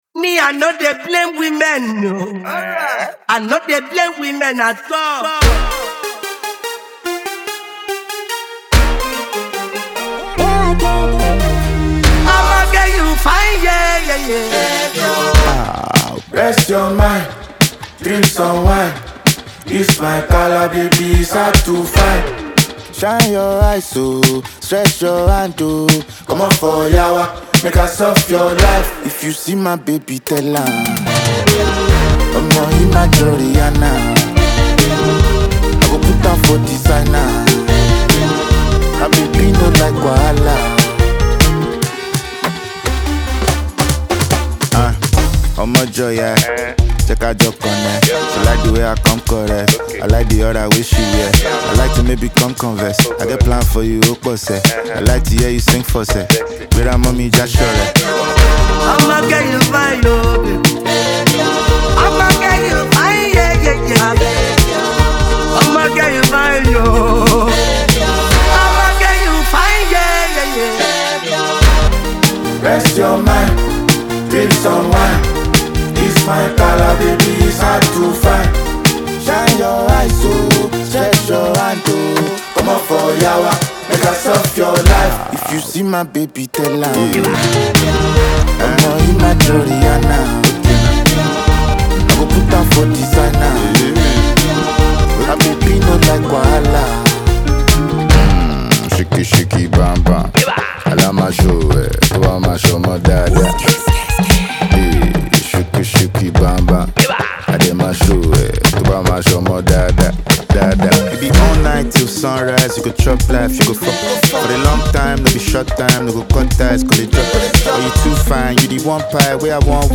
a smooth, infectious Afro-fusion rhythm